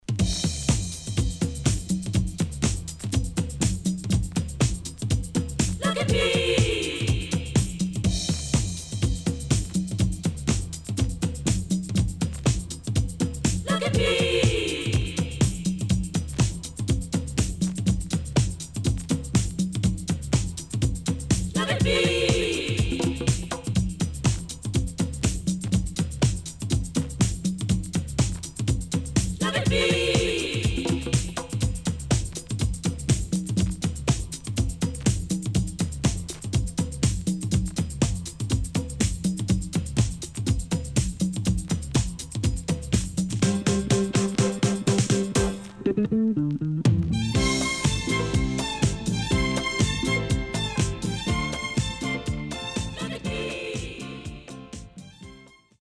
70's Disco Big Hit.VENUS DISCO Play.7038
SIDE A:少しチリノイズ入ります。